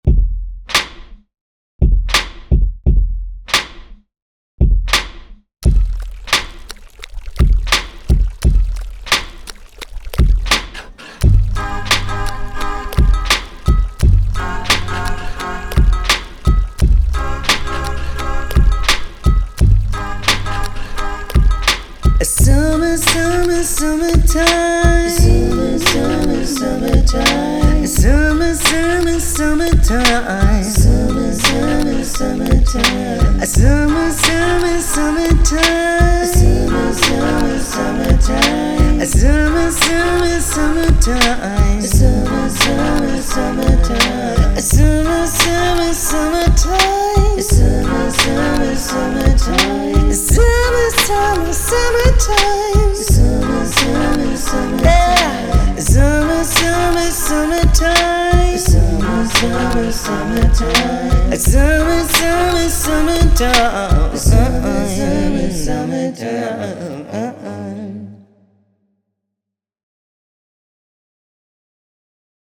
eine einzigartige Mischung aus Soul und Electro
Im dritten und letzten Sommermagazin sind die beiden in St. Gallen unterwegs. Unter anderem sammeln sie Klänge ein, jammen und loopen und singen sogar zusam-men.